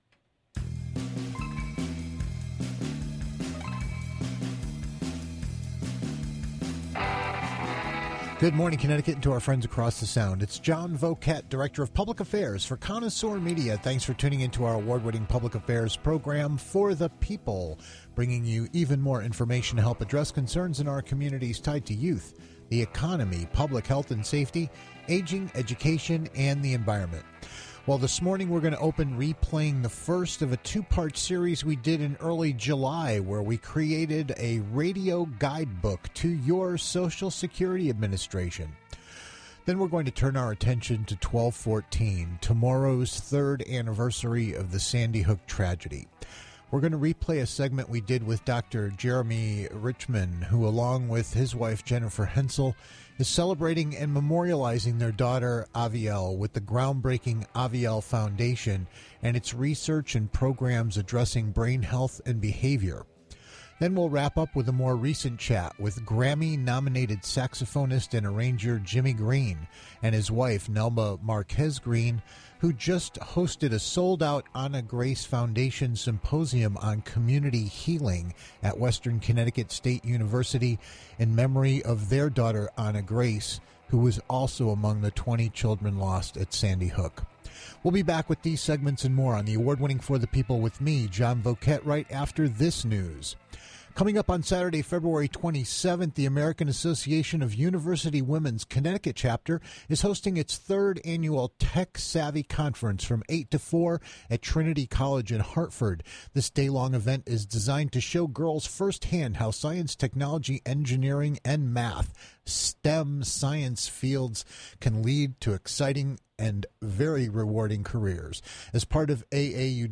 two interviews